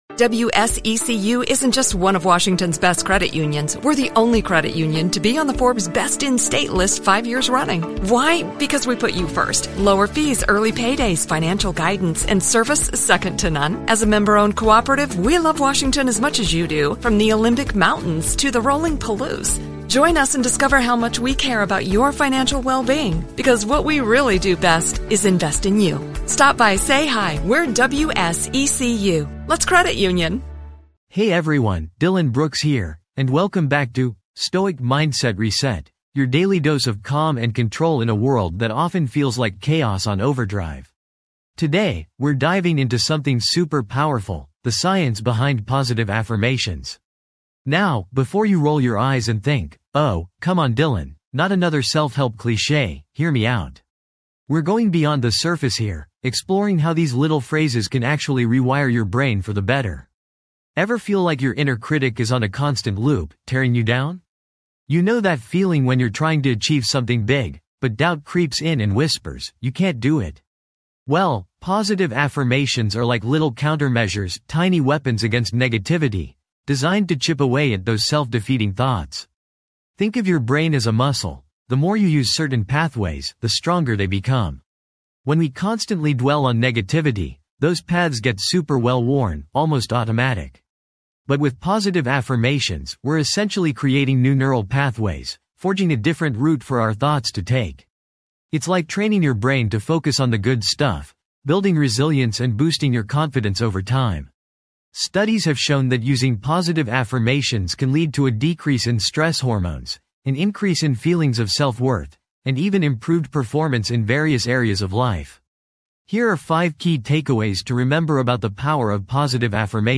- Experience a guided affirmation practice to foster positivity
This podcast is created with the help of advanced AI to deliver thoughtful affirmations and positive messages just for you.